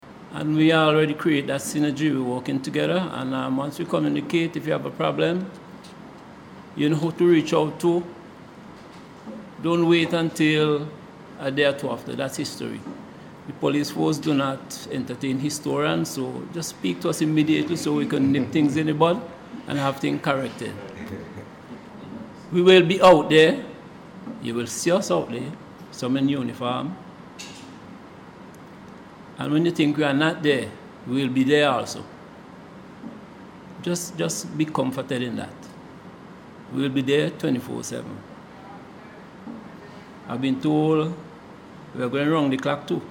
This assurance was given by Assistant Commissioner of Police in charge of Operations, Christopher Benjamin during a recent press conference hosted by the Carnival Development Corporation (CDC).